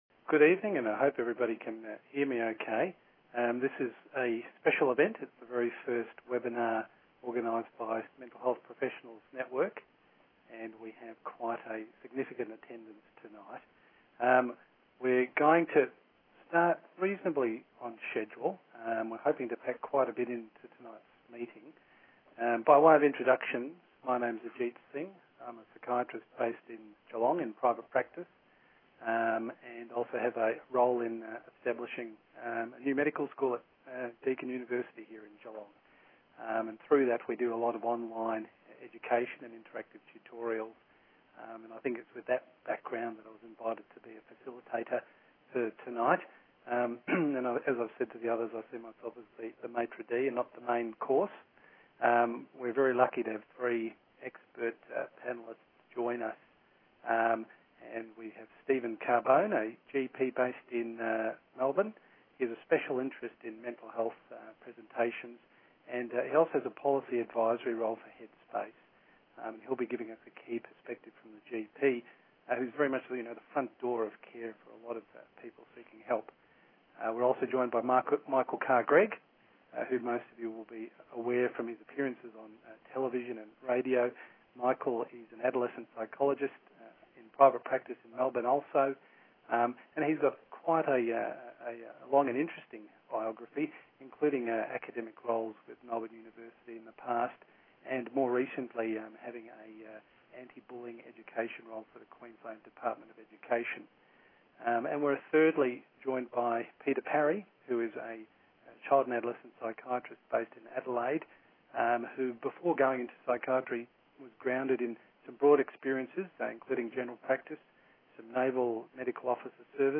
This webinar features a facilitated interdisciplinary panel discussion around strategies for diagnosing and building resilience.